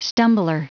Prononciation du mot stumbler en anglais (fichier audio)
Prononciation du mot : stumbler